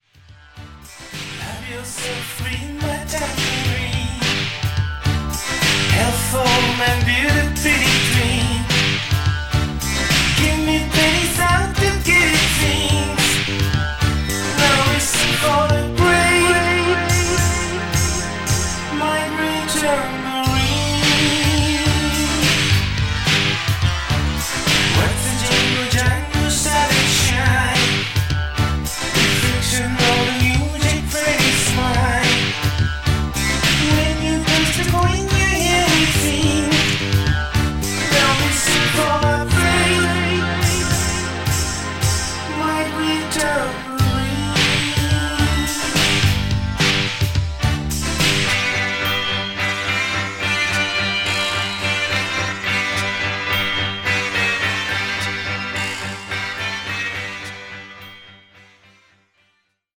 ’60’~’70年代のポップ/ソウル・ヒットを'80年代のサウンド・センスでカバーした好盤です。